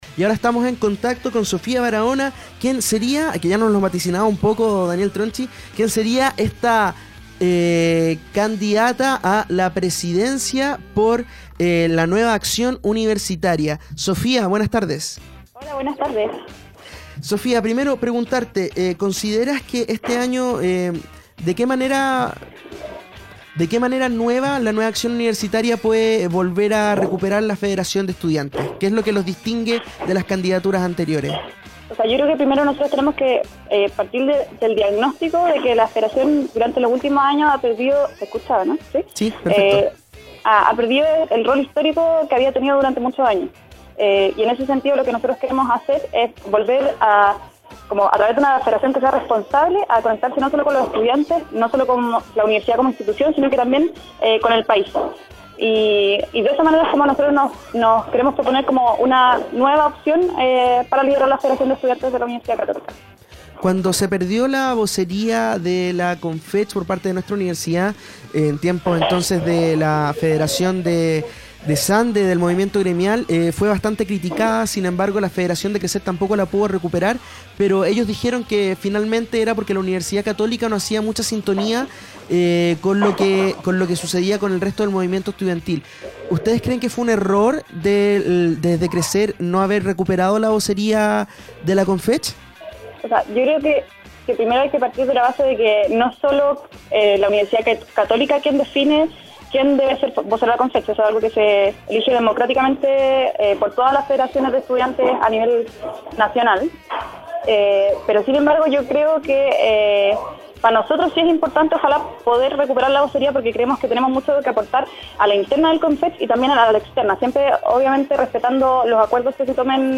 ¡Revisa la entrevista completa que preparó el equipo de Página 33! http